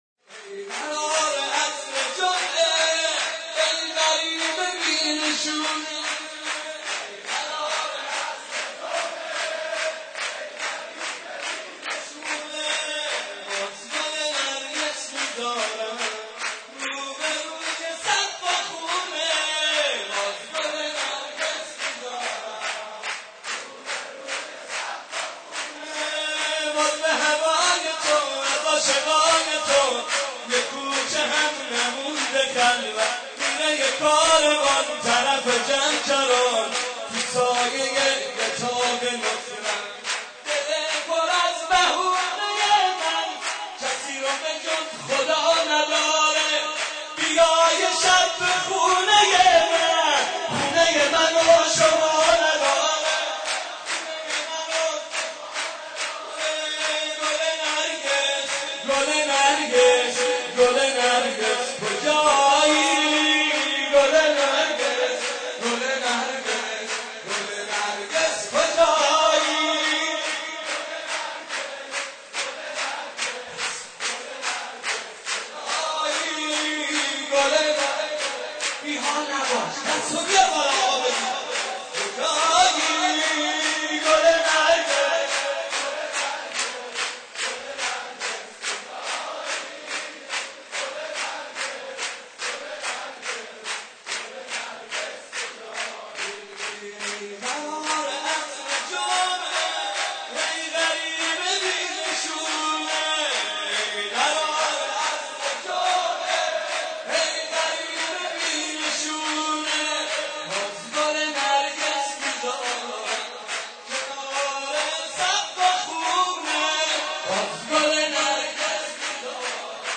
عنوان : سرود ولادت امام زمان (عج)